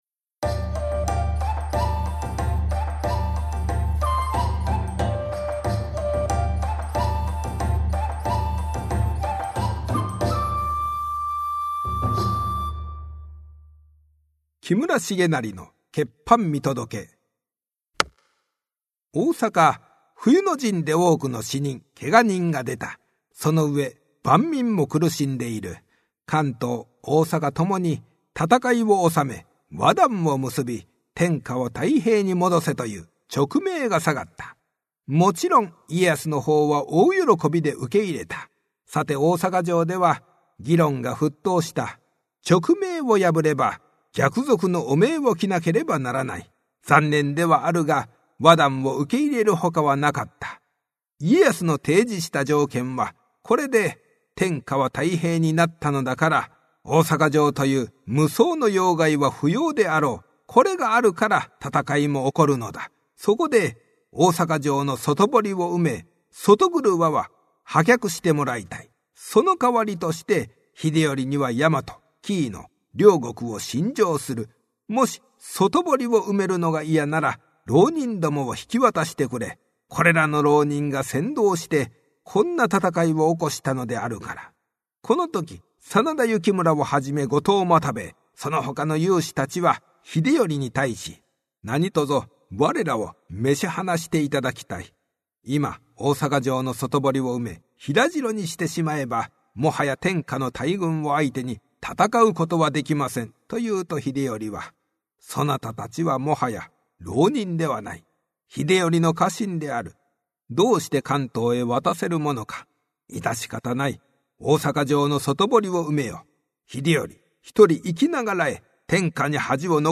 [オーディオブック] こども講談 十二
講談師。